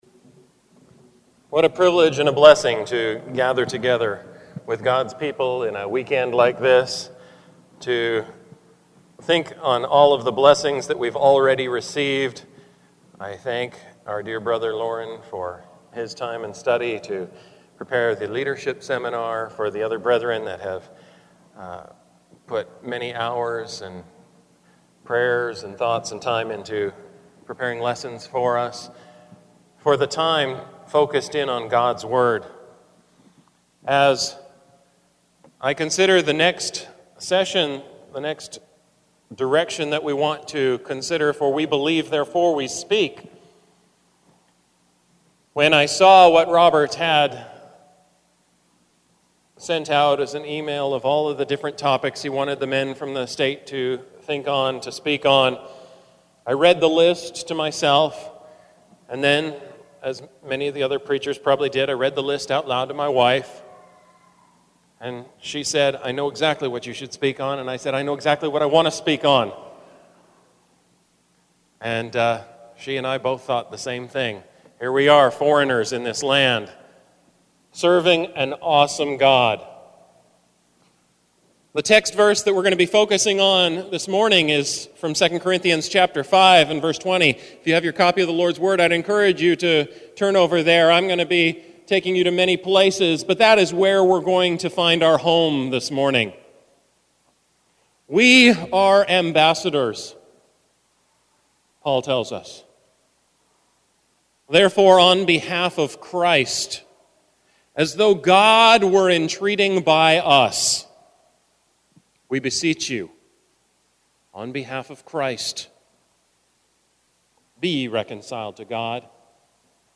Sermons
2014 Lectureship 2014 Hits